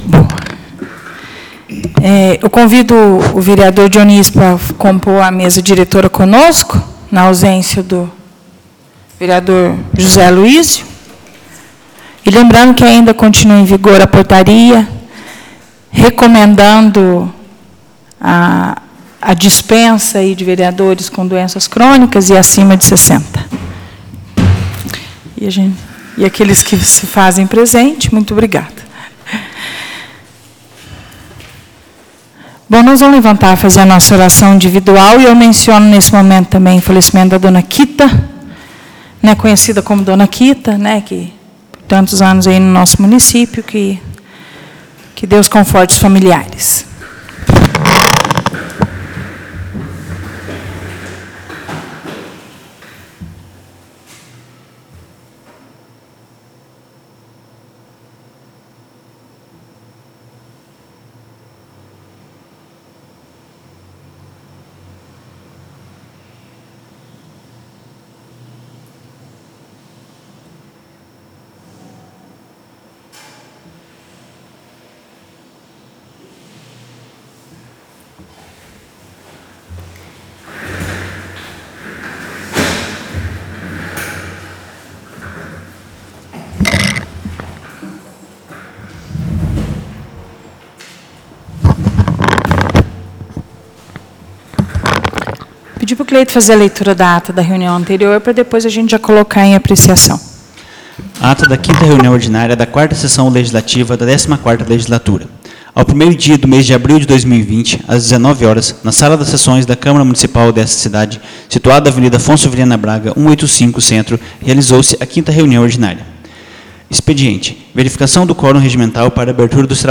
Áudio produzido com a gravação da 6ª Reunião Ordinária, realizada no dia 15 de abril de 2020.